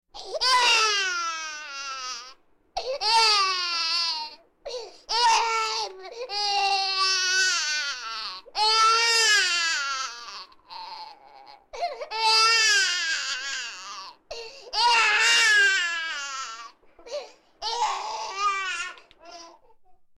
Звук первого крика новорожденного после родов